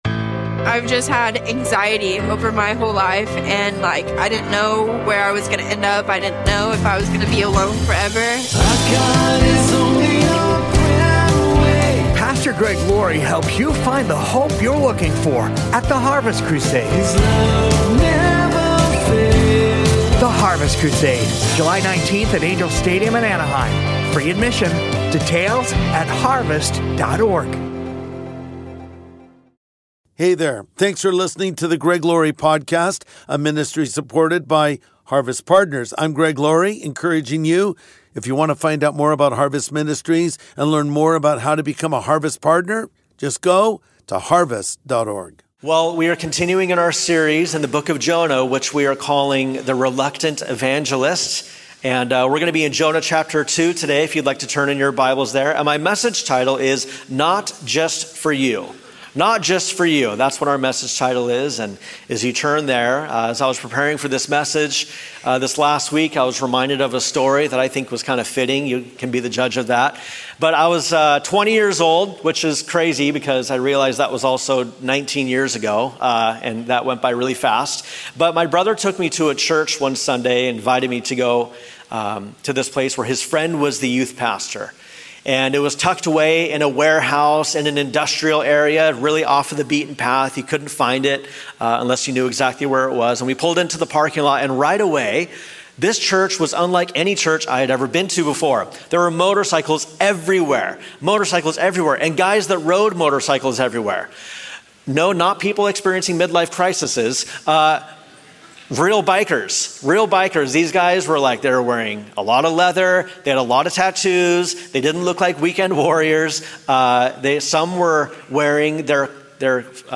Not Just for You | Sunday Message